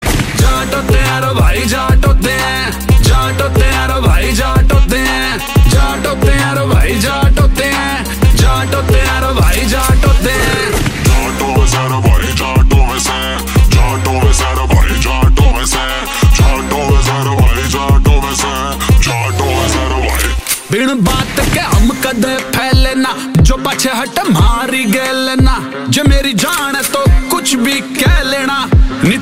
Haryanvi Songs
• Simple and Lofi sound
• Crisp and clear sound